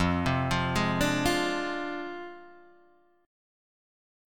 Fm6 chord